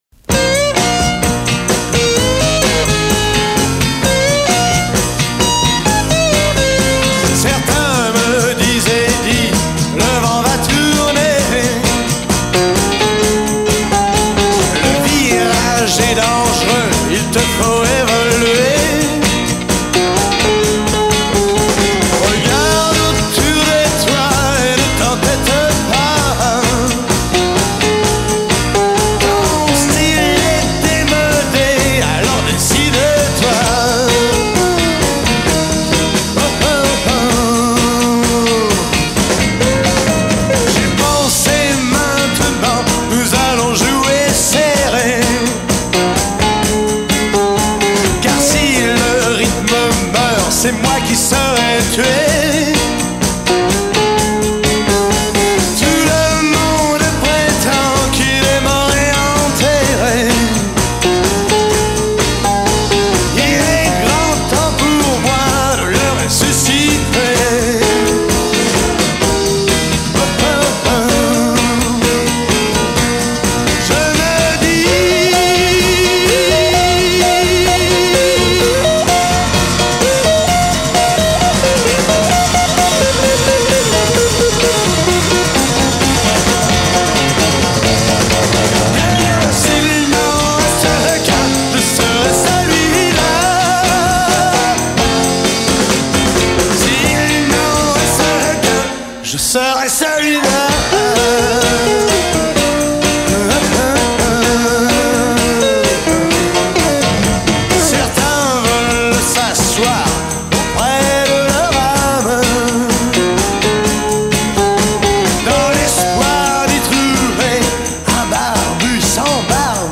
Rocker dans l’âme